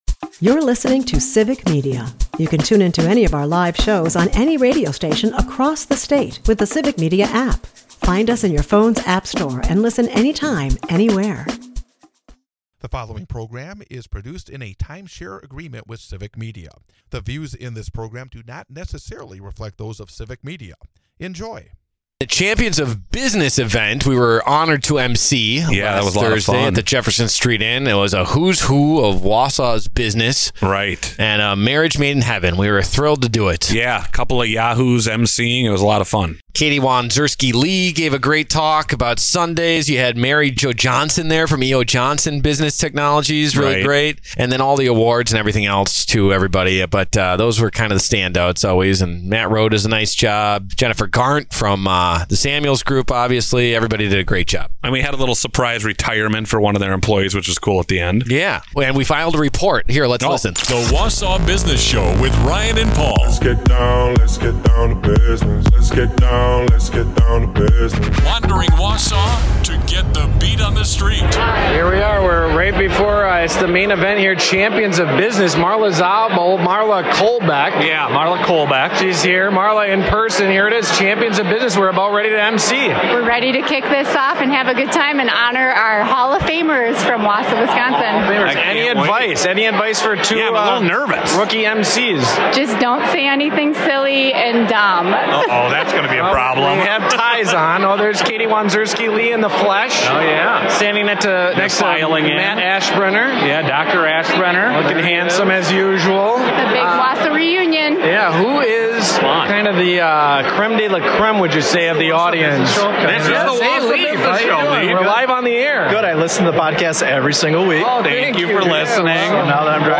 -Champions of Business Report The Wausau Business Show is a part of the Civic Media radio network and airs Saturday from 8-9 am on WXCO in Wausau, WI.